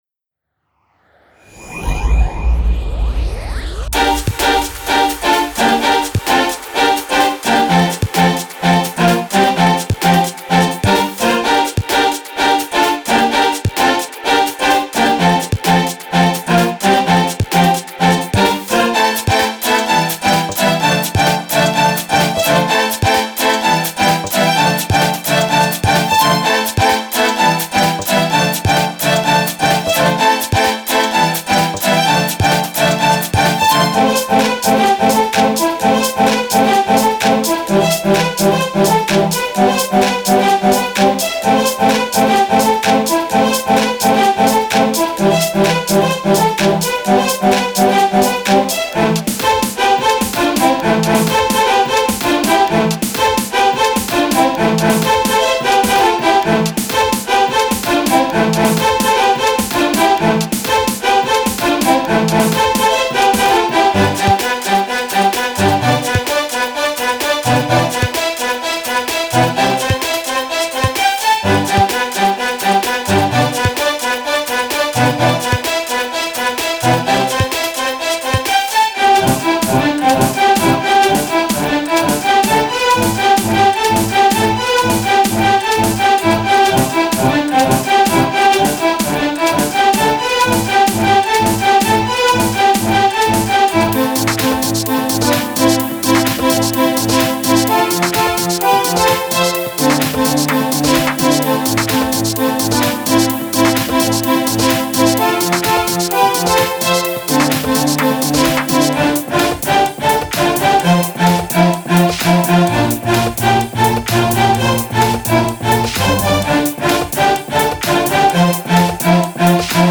所有这些MIDI和WAV旋律均带有键标记，并以128 BPM的速度录制。
•21 WAV 8小节弦乐旋律
•128 BPM